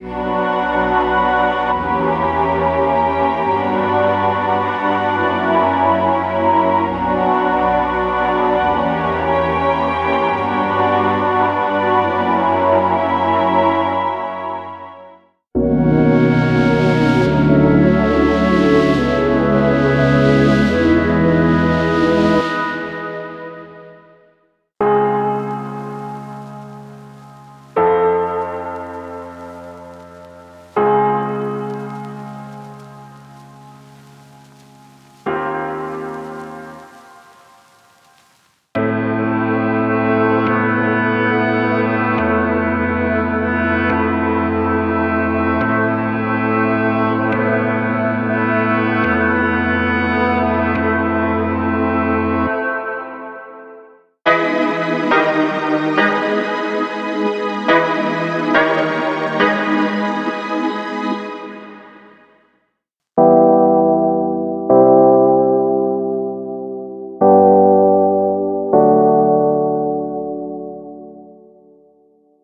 MIDI Chord Progressions Demo